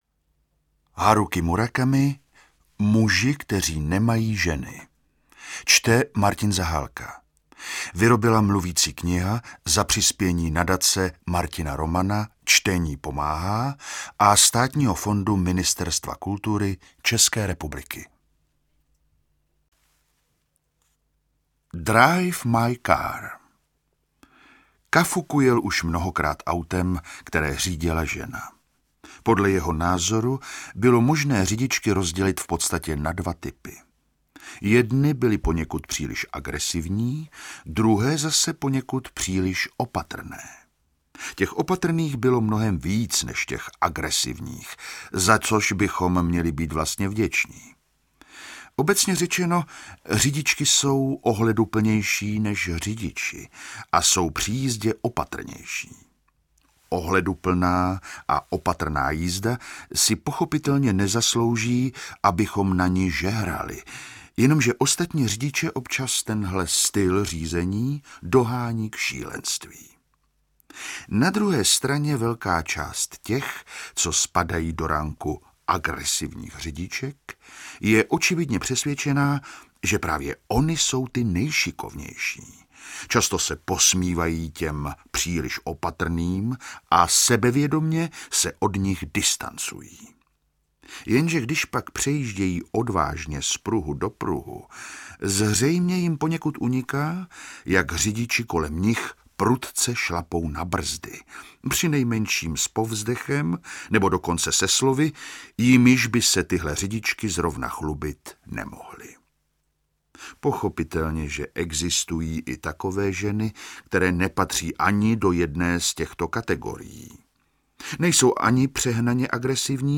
Čte: Martin Zahálka